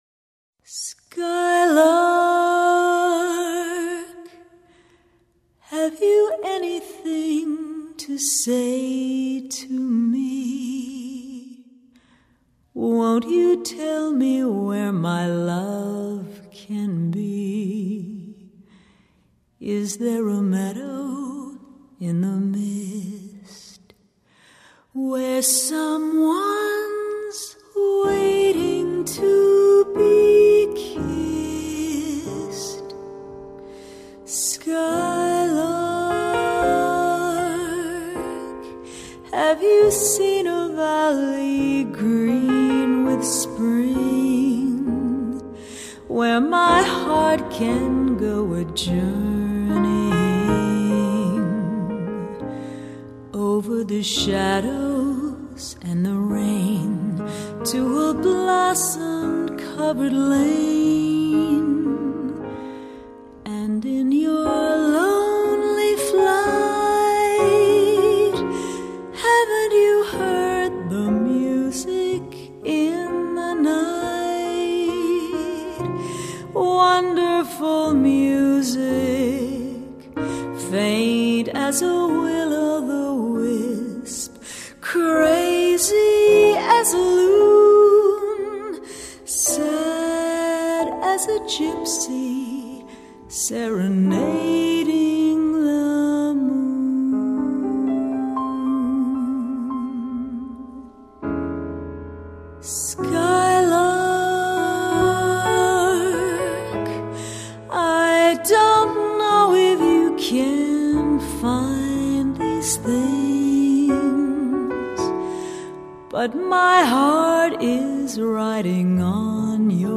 音樂類型：爵士樂